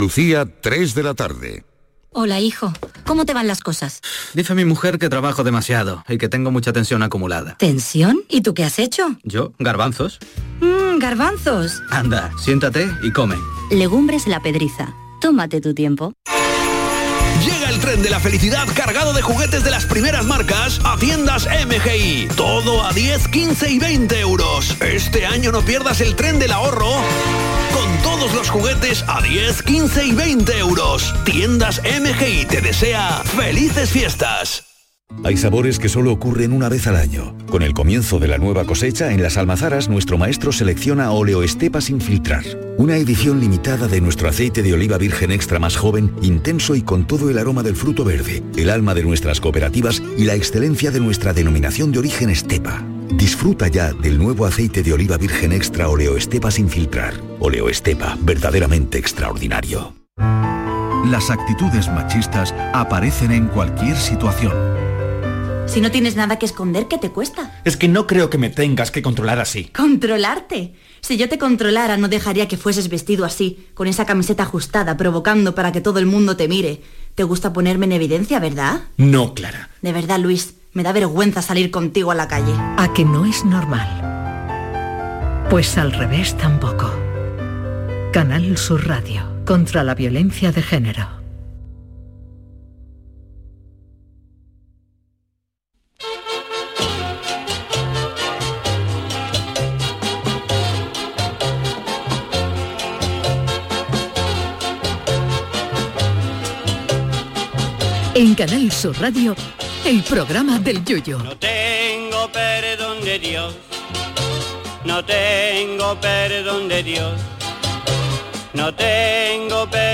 ¿Por qué no te tomas un rato para ver la realidad y vida con otros ojos?. Escucha la versión más surrealista de la actualidad y la música que no te imaginas. Con el humor más delirante.